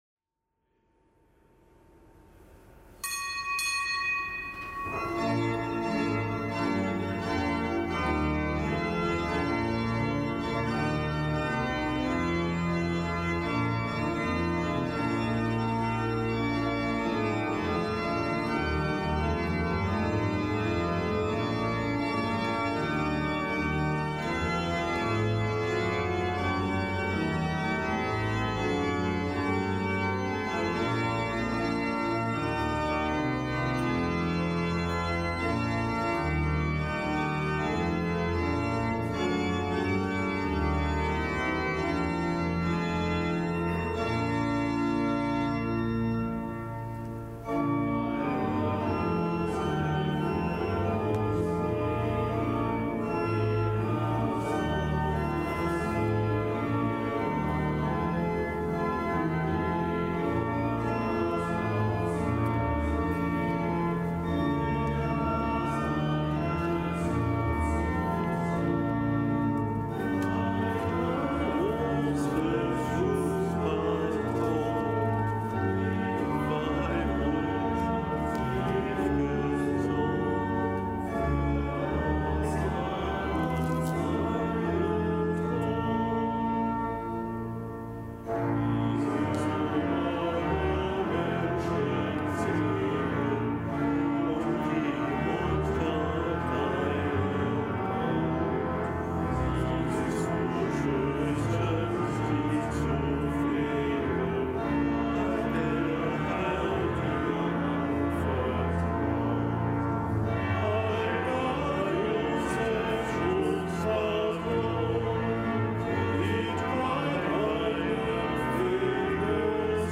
Kapitelsmesse aus dem Kölner Dom am Hochfest des Hl. Josef am Mittwoch der zweiten Fastenwoche. Zelebrant: Weihbischof Rolf Steinhäuser.